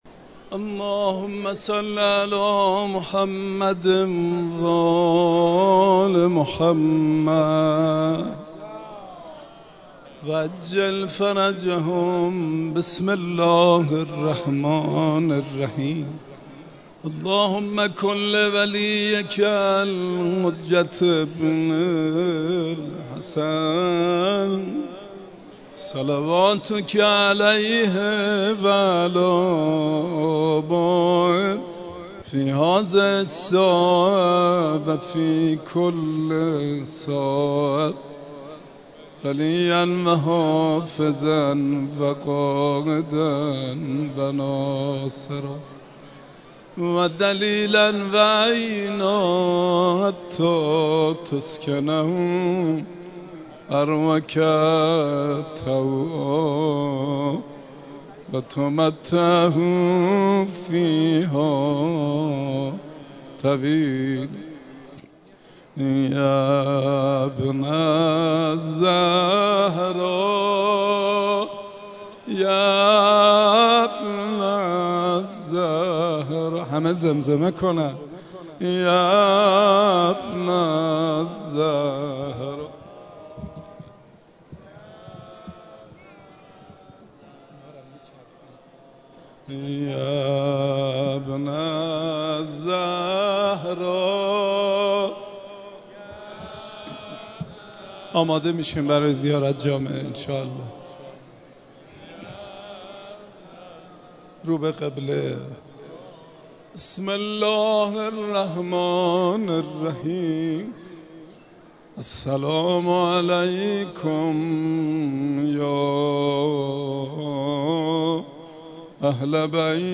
زیارت جامعه کبیره - حرم حضرت فاطمه معصومه (س) با ترافیک رایگان